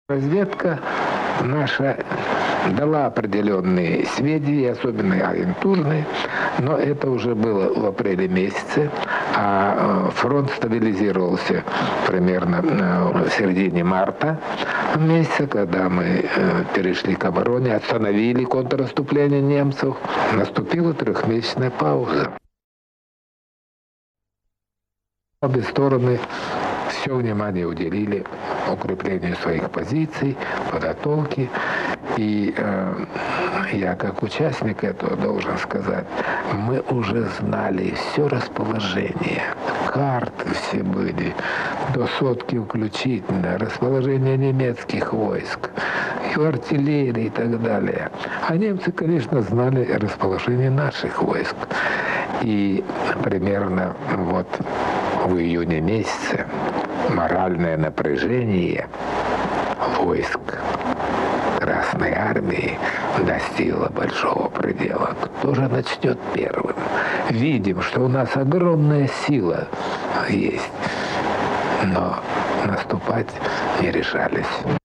(Архивная запись)